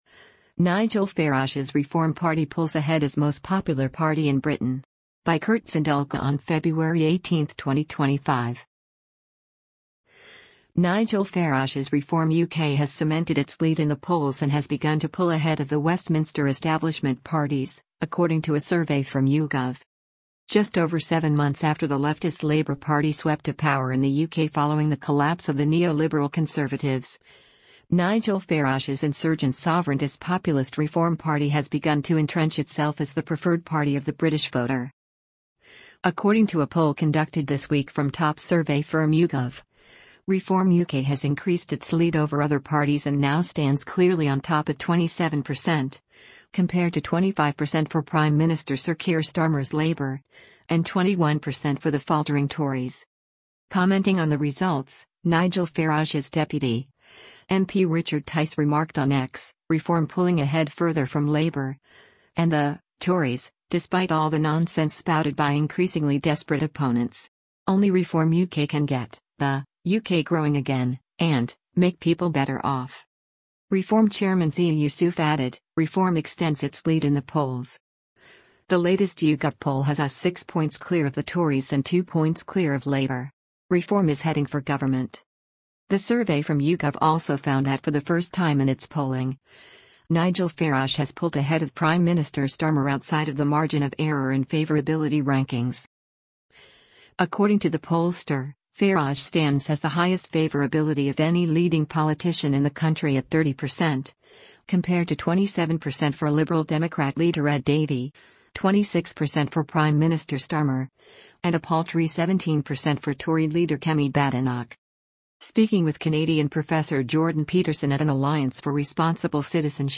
Reform UK leader Nigel Farage speaks during an interview with Jordan Peterson at the Allia